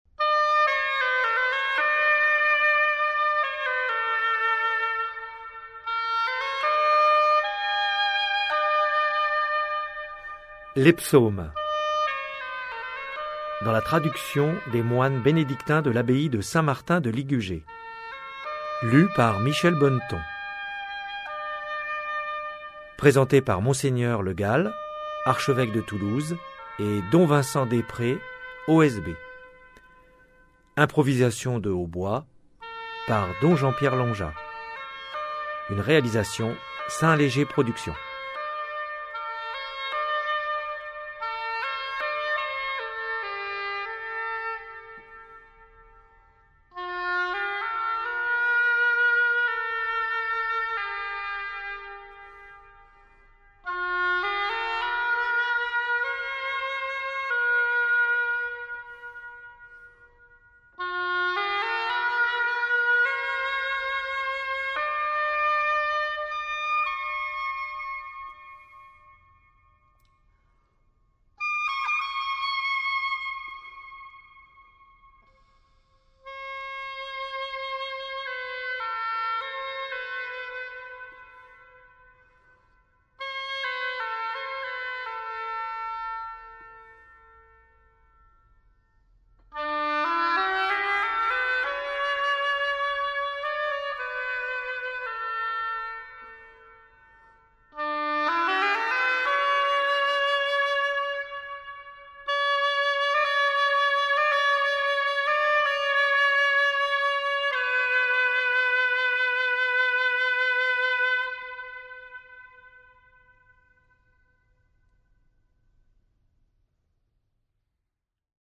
Get £2.21 by recommending this book 🛈 Une magistrale association de deux talents : la voix du hautbois d'un moine bénédictin et celle d'un comédien, tous deux habitués de longue date à la récitation de cet ancestral trésor religieux où grâce à une indexation raisonnée chaque chrétien pourra puiser chaque jour l'eau vive en communion avec les lectures du jour.